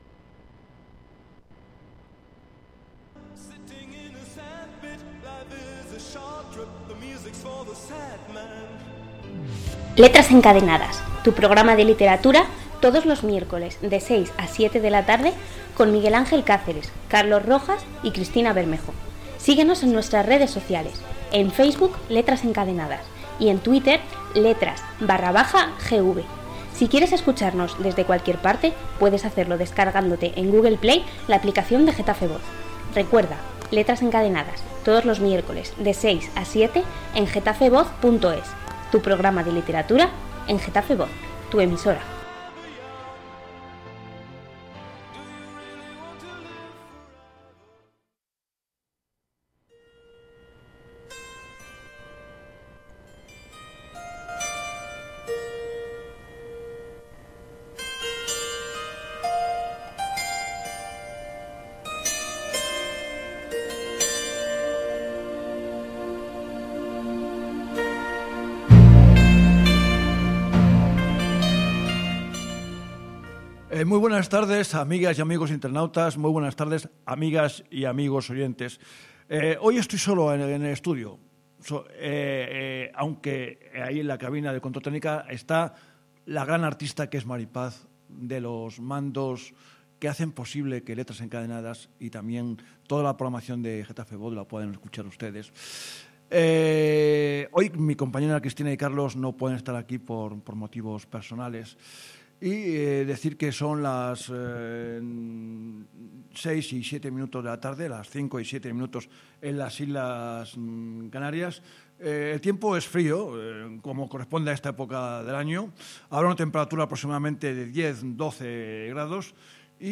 Radio: Podcast GetafeVoz, en Letras Encadenadas. Una deliciosa entrevista sobre Amor del Bueno. 5 Noviembre 2014